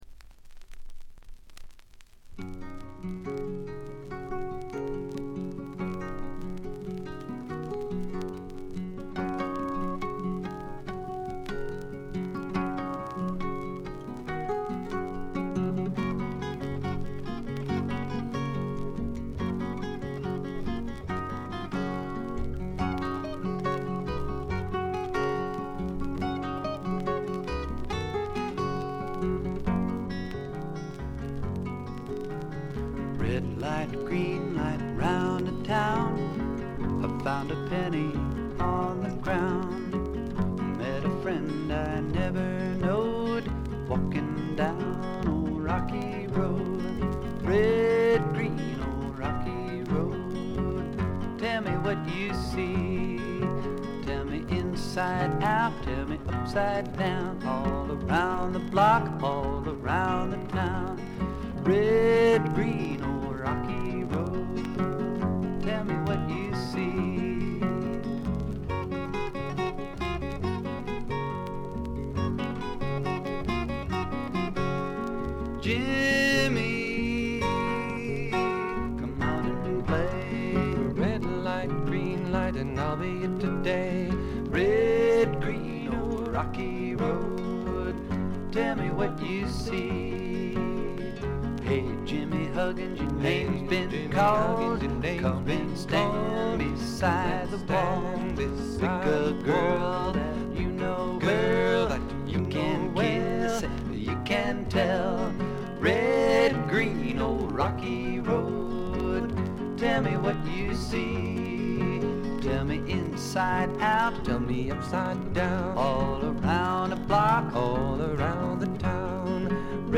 バックグラウンドノイズやチリプチ、プツ音等多め大きめ。
試聴曲は現品からの取り込み音源です。